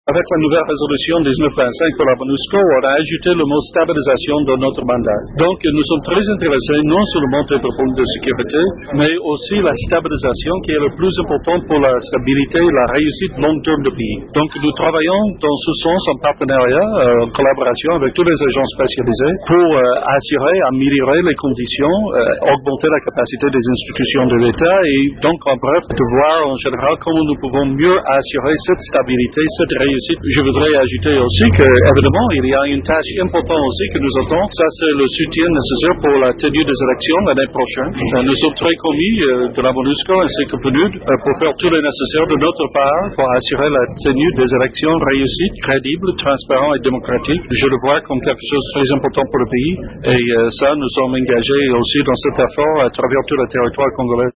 M. Meece évoque ici l’apport de la Monusco dans la stabilisation de la paix et l’organisation des prochaines élections en RDC :